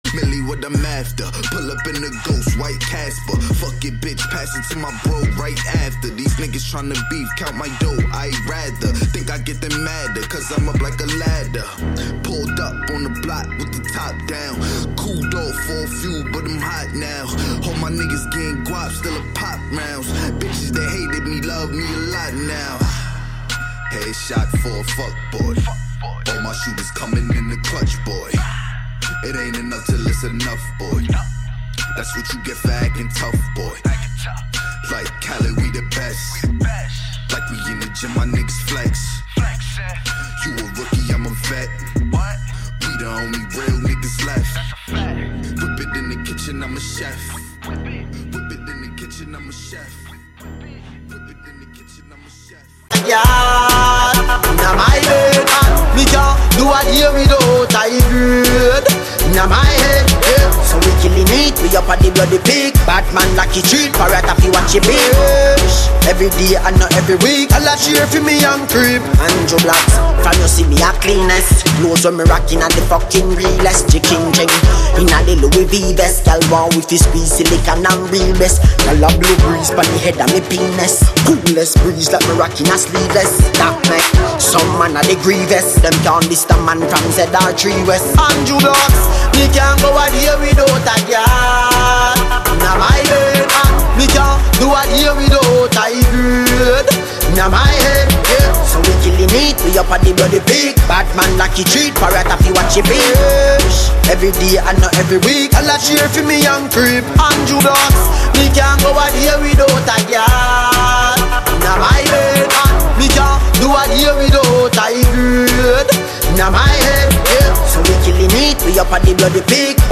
Hiphop
Smooth and on point!